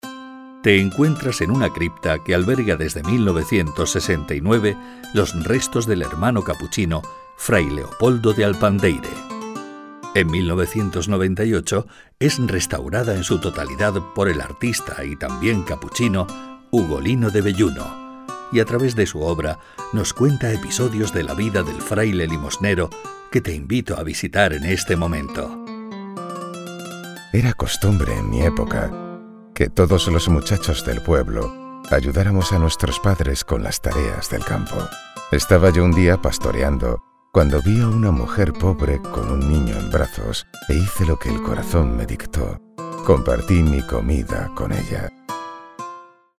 audioguide voice over